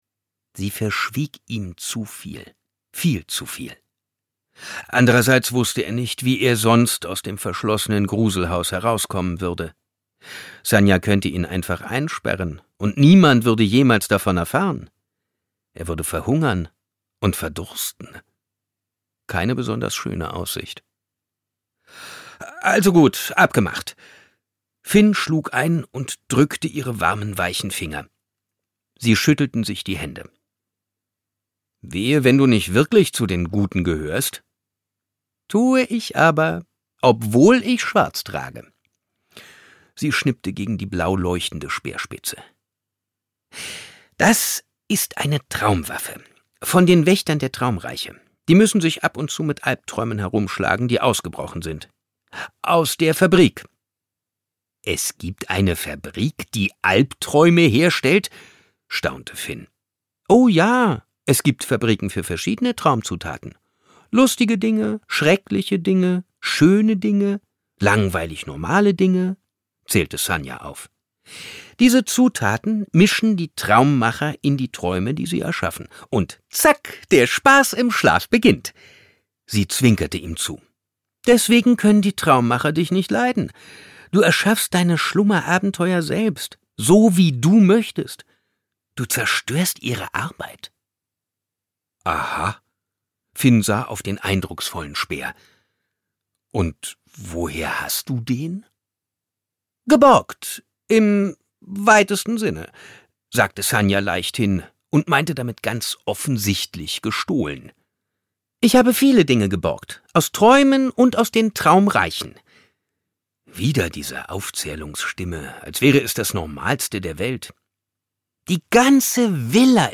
Die Traumgänger Aufbruch nach Deseo Markus Heitz (Autor) Simon Jäger (Sprecher) Audio-CD 2024 | 2.